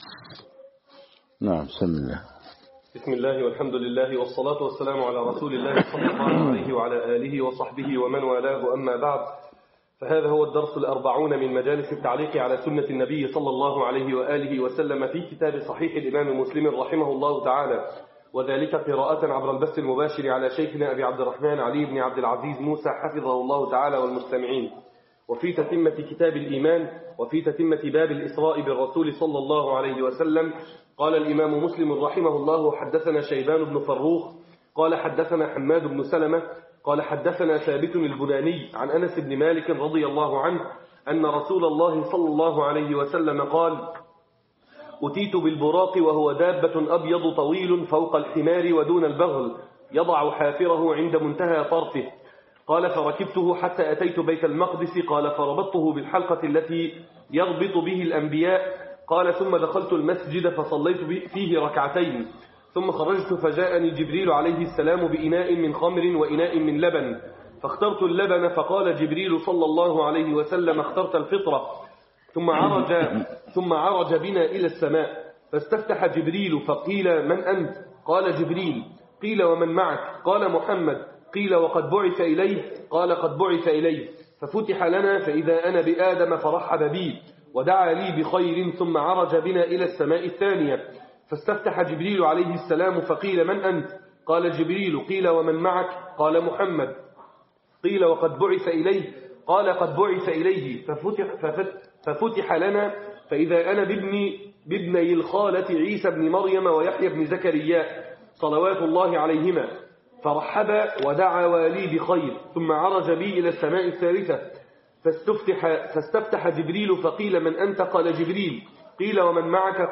الشروحات العلمية